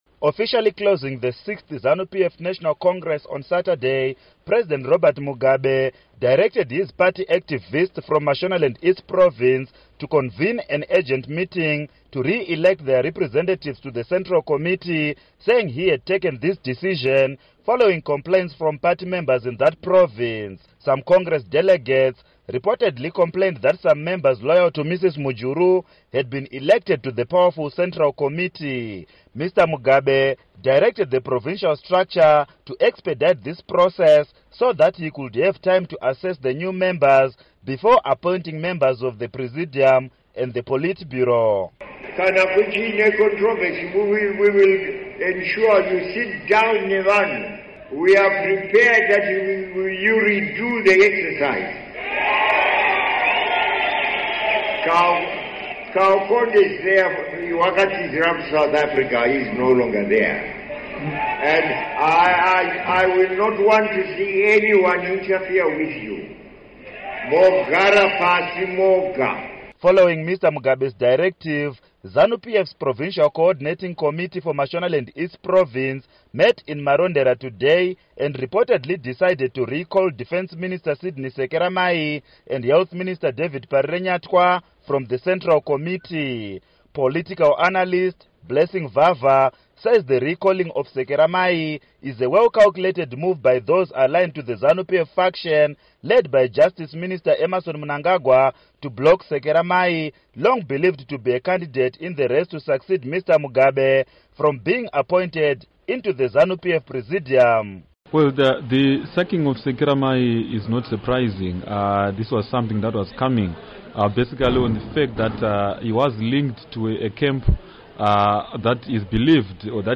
Report on Continuing Zanu PF Factionalism